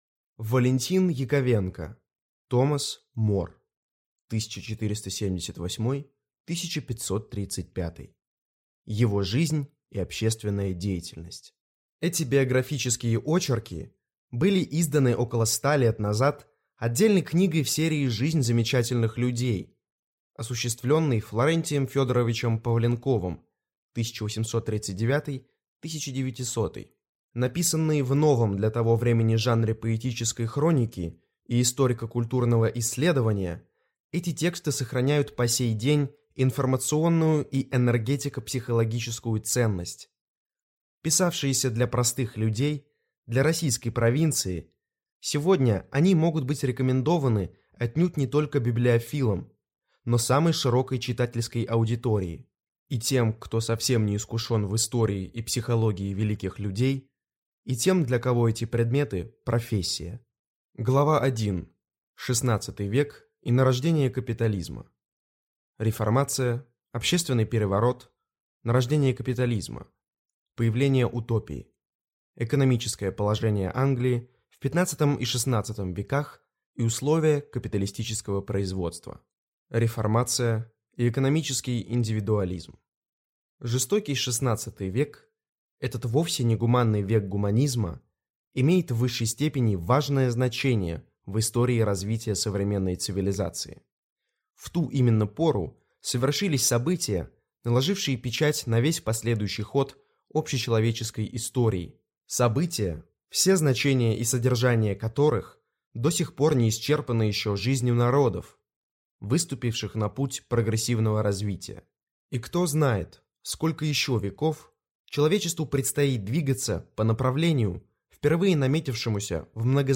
Аудиокнига Томас Мор (1478-1535). Его жизнь и общественная деятельность | Библиотека аудиокниг